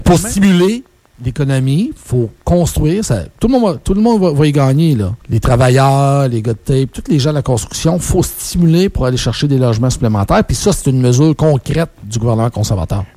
En entrevue à Radio Beauce